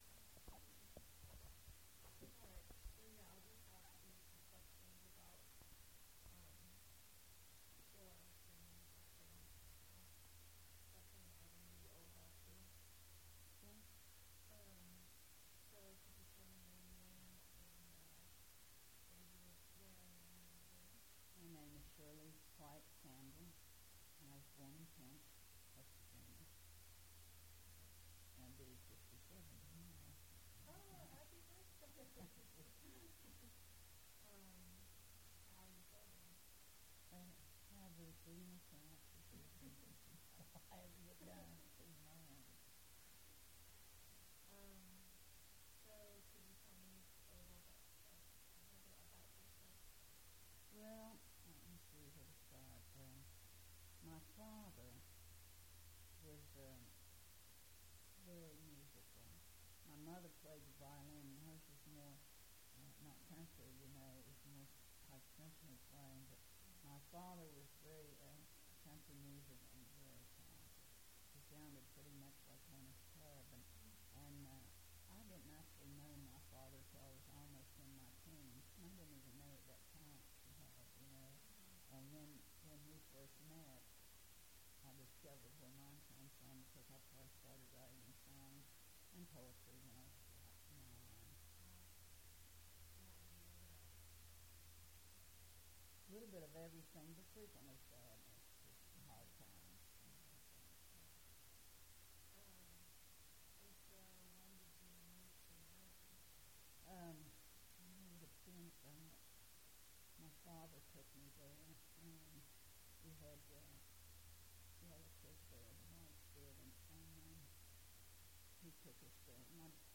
Oral history
Location: Charleston (W. Va.)